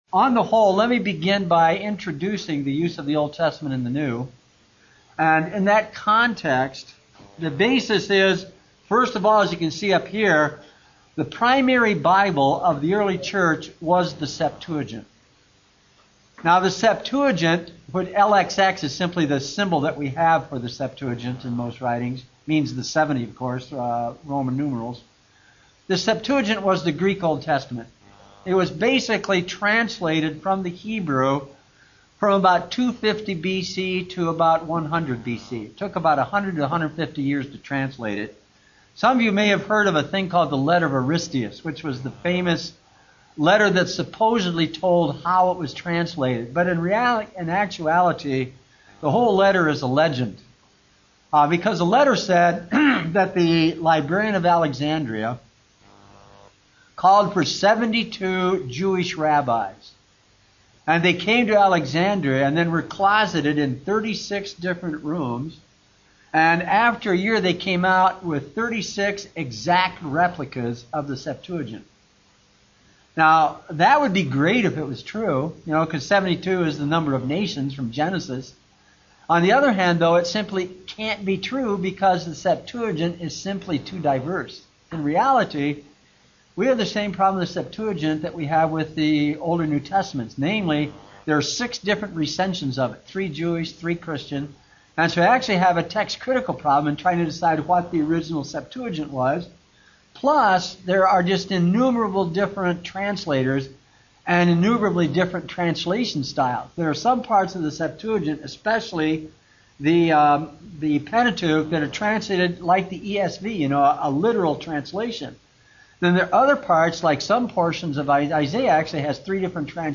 Address: The Meaning and Power of Fulfillment: the Use of the Old Testament in Matthew Recording Date